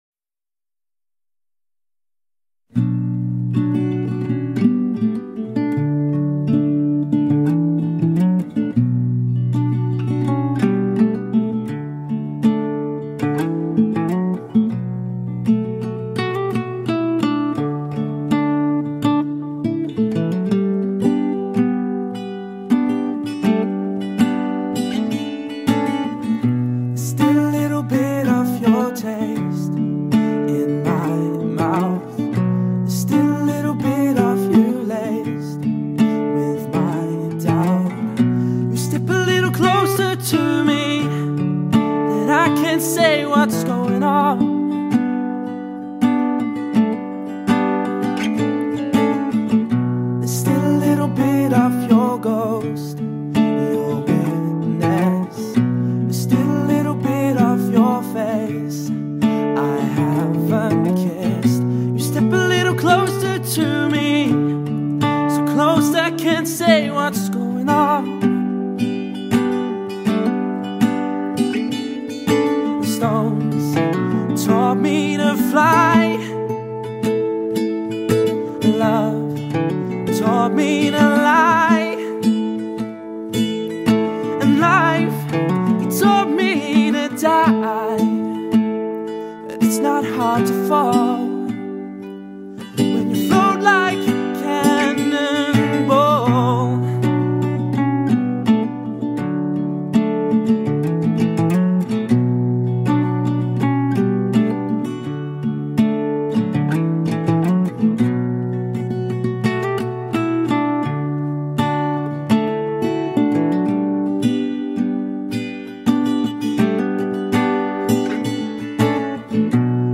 singer and acoustic guitarist